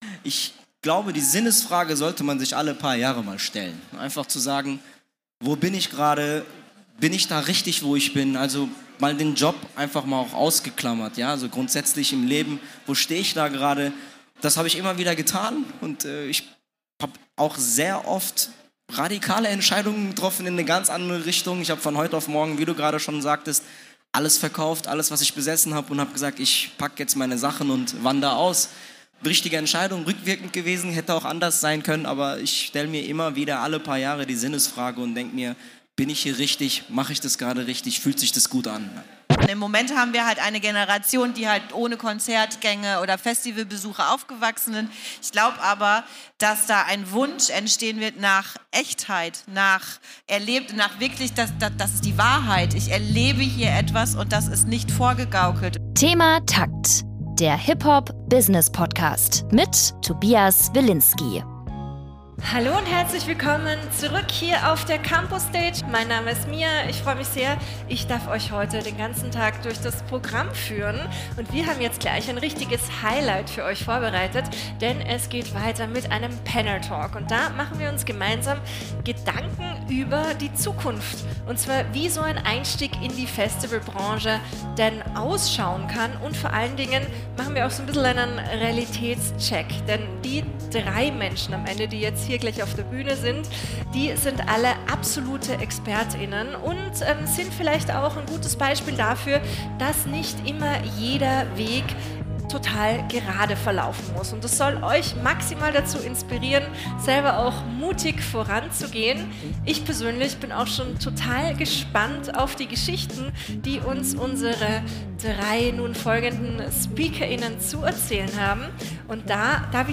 Das Panel haben wir am 27.11.2025 auf der Future of Festivals-Konferenz vor Live-Publikum in Berlin aufgenommen.